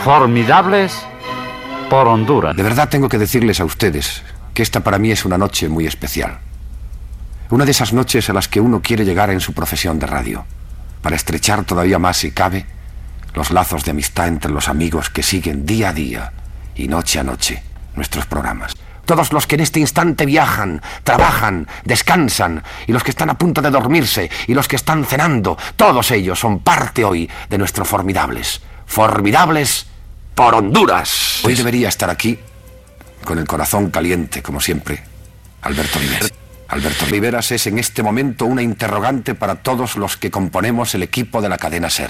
Presentació del programa especial dedicat a ajudar als damnificats d'Hondures.
Presentador/a